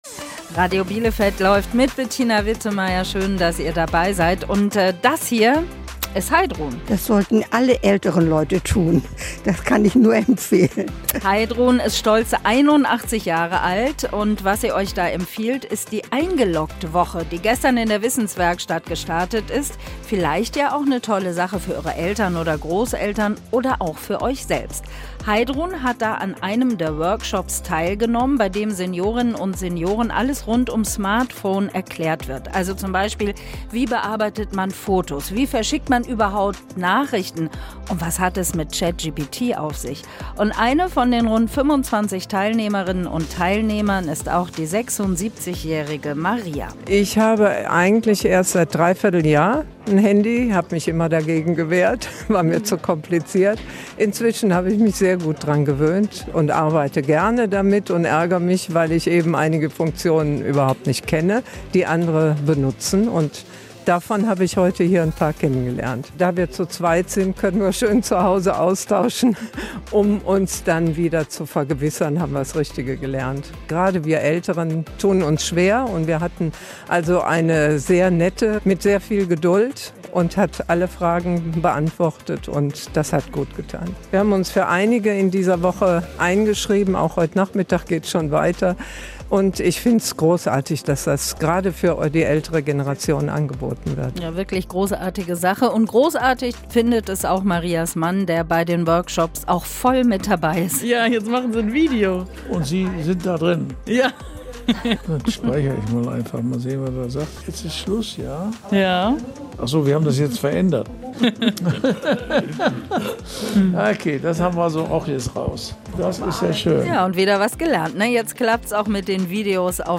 Teilnehmer*innen im Interview mit Radio Bielefeld